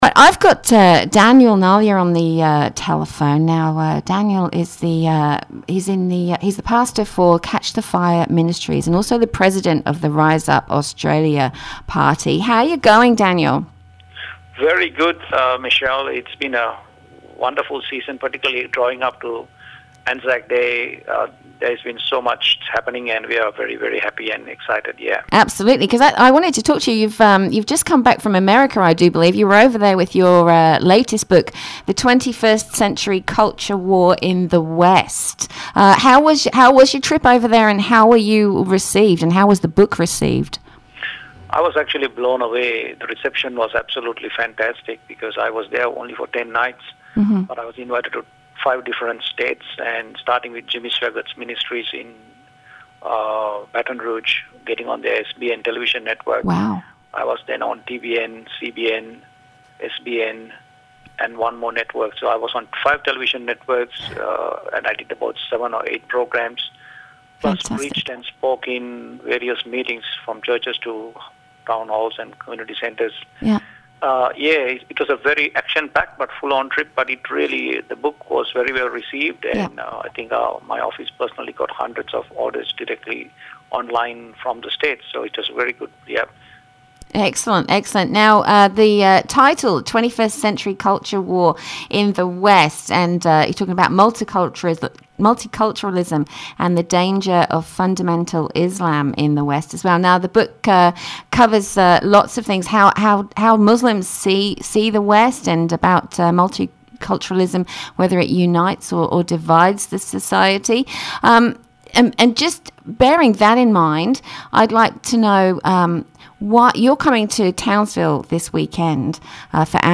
Townsville Radio Interview – Reformation Harvest Fire Ministries